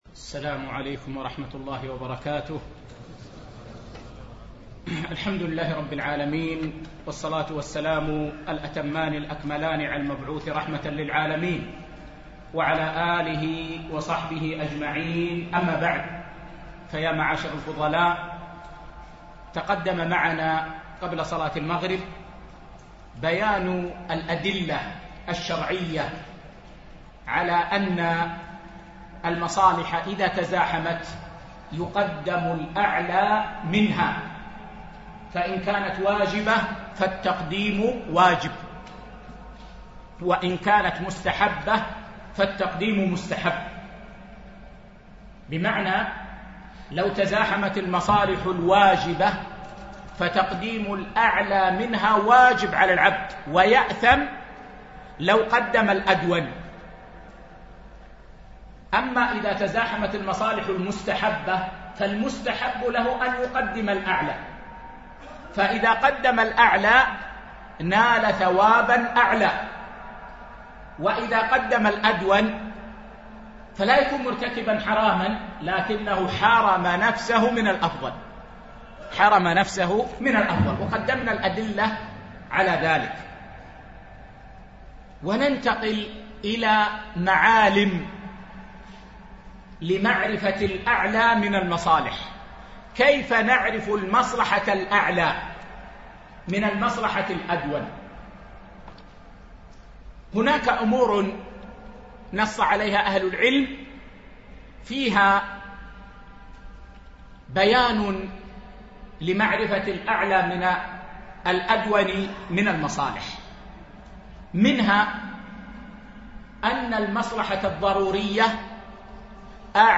فقه المصالح والمفاسد ـ الدرس الرابع